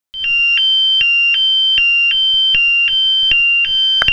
SIRENA ELECTRÓNICA CON AJUSTE AUTOMÁTICO DE VOLUMEN - Ø 70MM
Sirena con ajuste automático de volumen según el nivel de ruido ambiente
Sonido Intermitente
De 80 a 100 dB
2500 Hz
K71- SIRENA MODULADA
K71- SIRENA MODULADA.wav